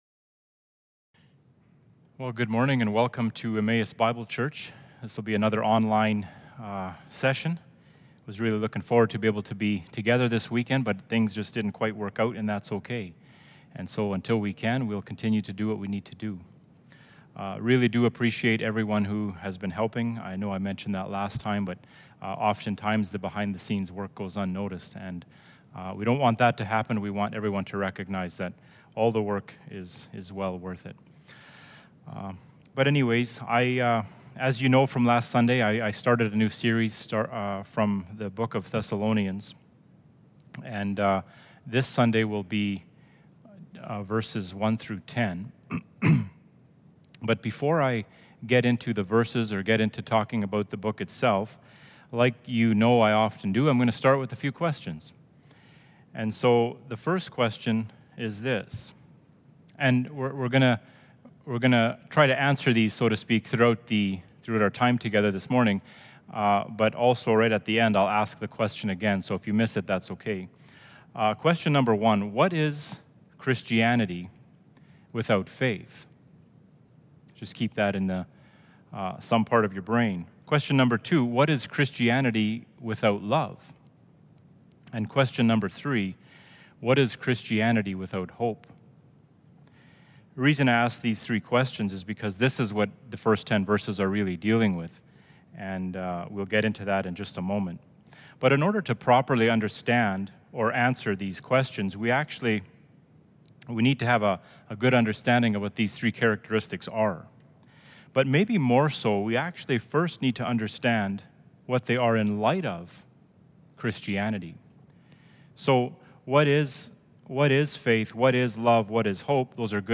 1 Thessalonians 1:1-10 Service Type: Sunday Morning